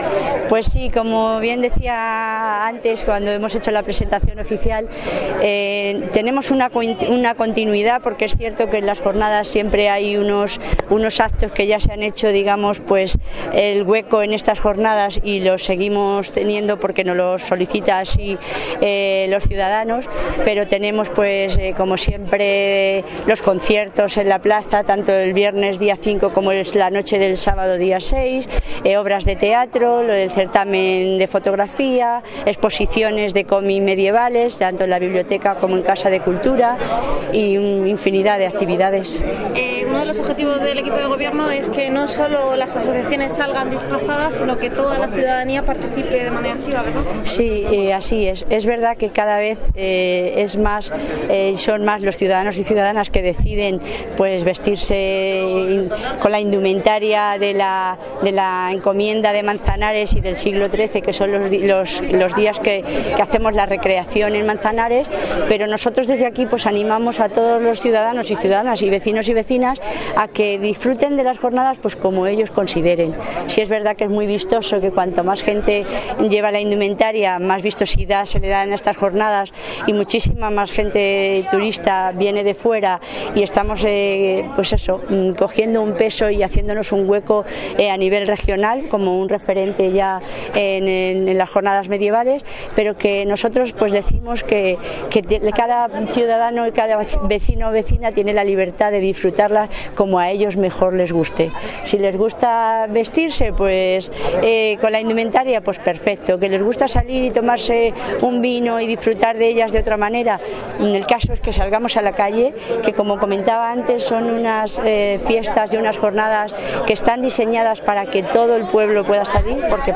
declaraciones_silvia_cebrian.mp3